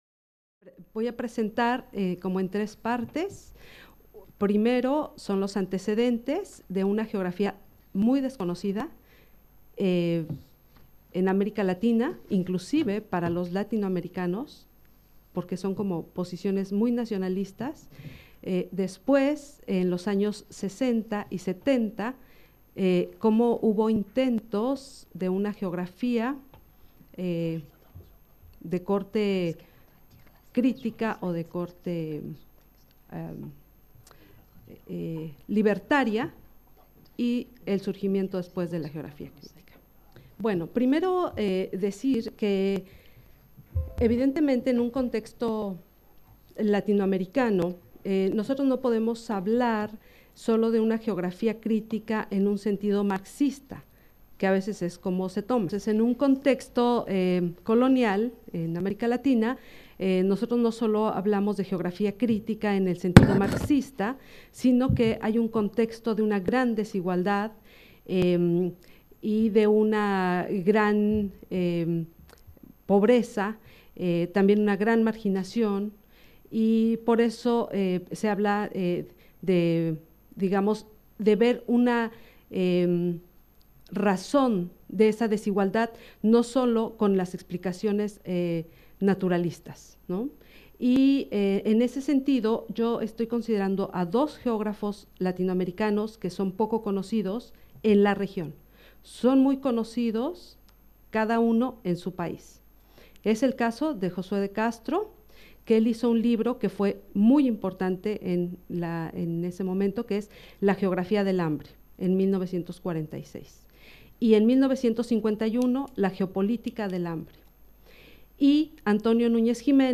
Cette communication a été donnée en conclusion du colloque Approches critiques de la dimension spatiale des rapports sociaux qui s'est déroulé à Caen du 26 au 28 juin 2019. Le point de départ de ce colloque est la dynamique et la visibilité récentes des approches critiques des rapports sociaux dans la géographie française, alors que les analyses mettant l’accent sur les inégalités et les rapports de pouvoir et de domination sont incontournables depuis longtemps en géographie anglophone comme bien entendu en sociologie.